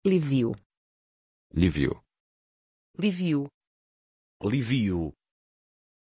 Aussprache von Lyvio
pronunciation-lyvio-pt.mp3